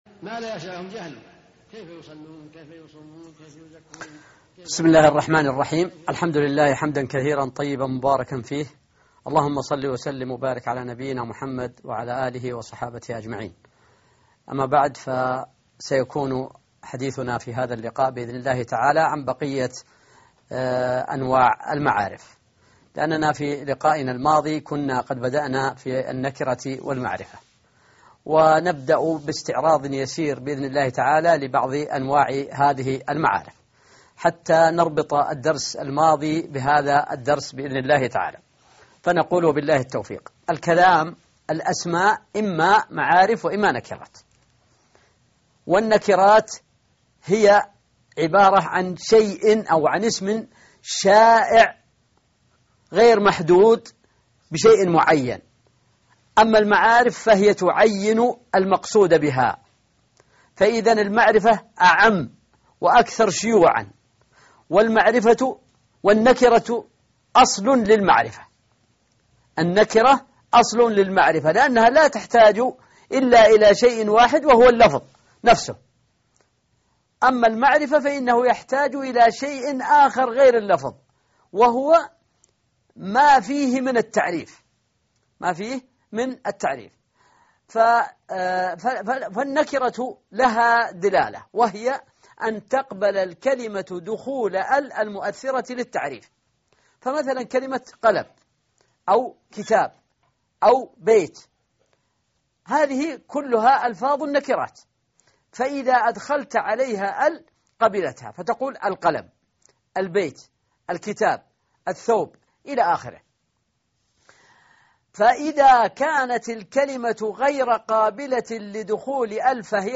الدرس 12 _ المعارف (23/4/2012) شرح الأجرومية